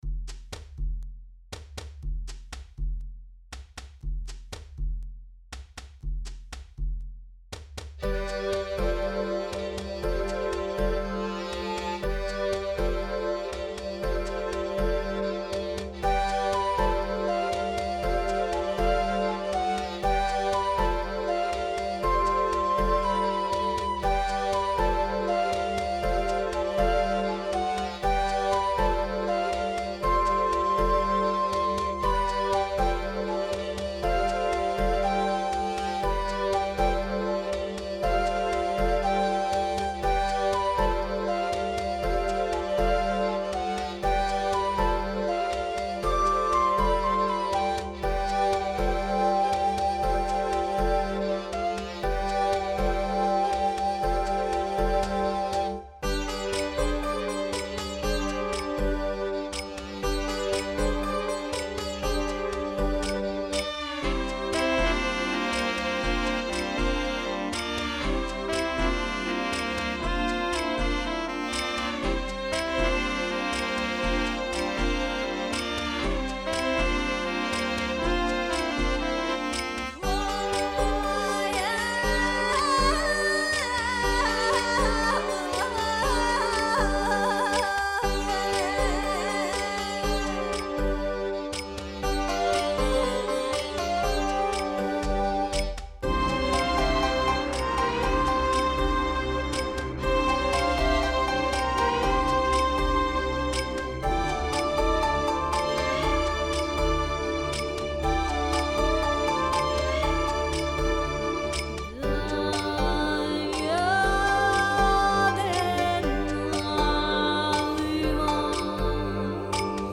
Complete arrangement